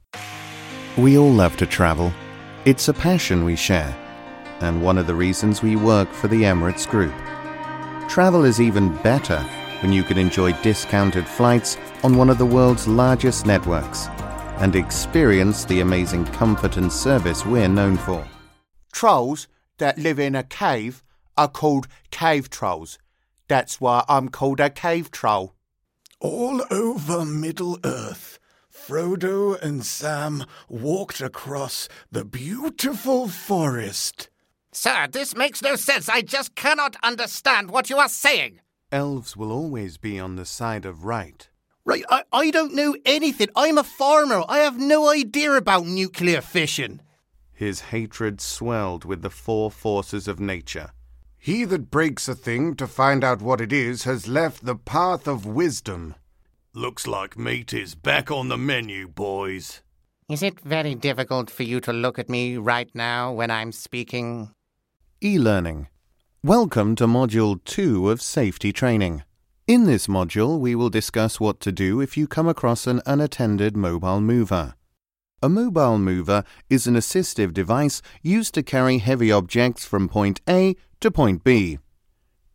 İngiliz İngilizcesi Seslendirme
Erkek Ses